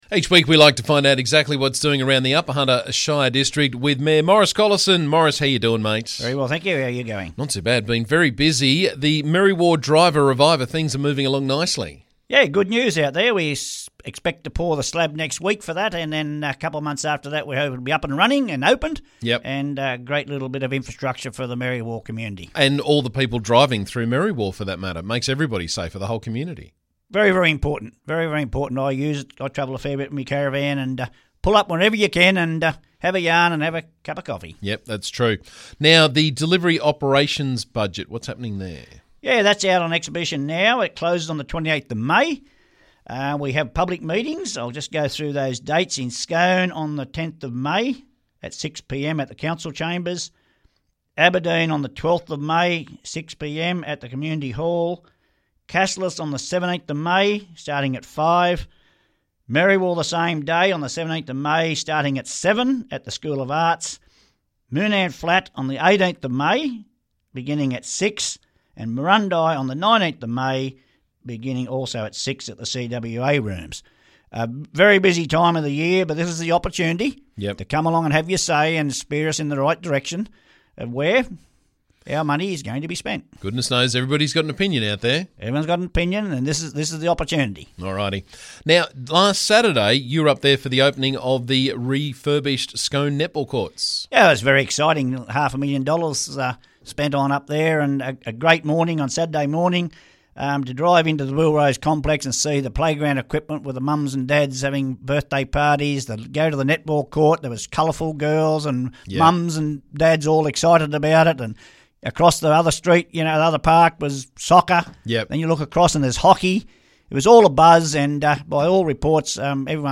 Upper Hunter Shire Council Mayor Maurice Collison caught us up with the latest from around the district.